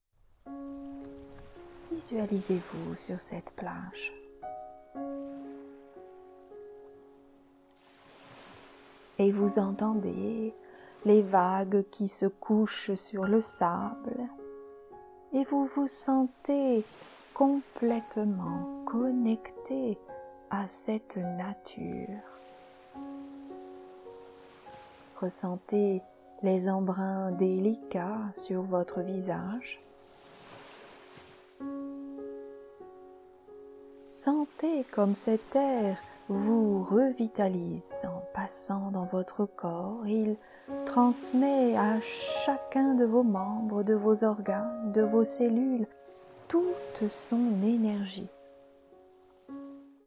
Cette relaxation guidée de 27 mn “Plage de Sérénité” a été conçue pour lever les tensions corporelles et mentales, pour retrouver le calme et l’apaisement.
Où que vous soyez, installez-vous confortablement et laissez-vous guider par ma douce voix 😀